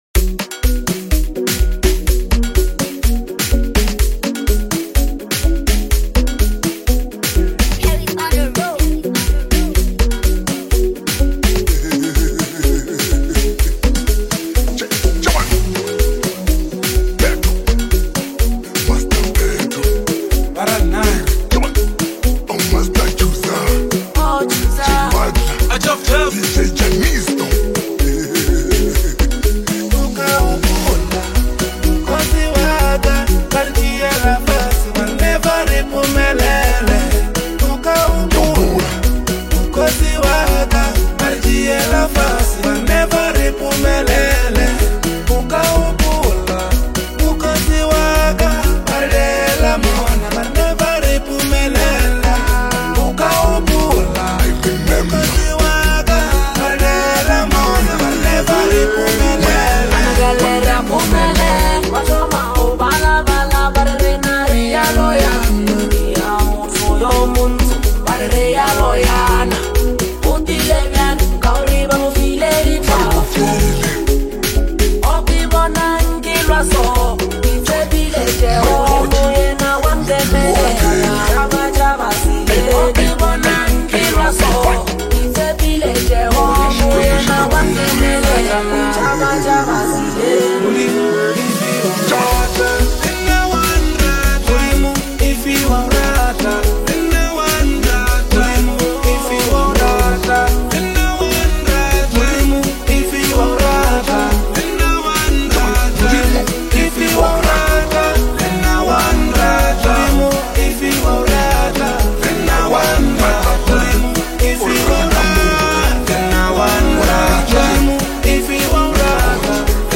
is a mesmerizing lekompo love ballad